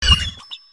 frigibax_ambient.ogg